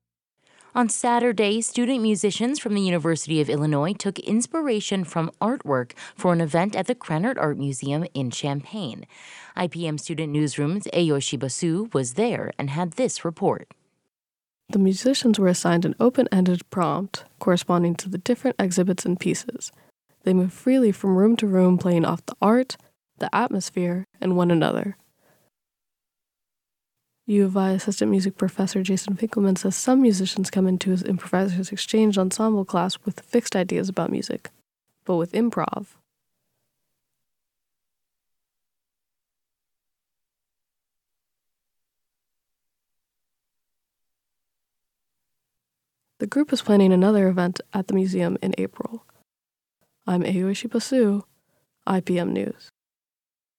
Student musicians at the University of Illinois at Urbana-Champaign took their musical improvisations to Krannert Art Museum on Saturday, Nov. 9, in a performance about how art can inspire sound.
For the finale, the ensemble performed together in the Rest Lab with a diverse array of instruments, from horn to melodica, ending on a unanimous hum.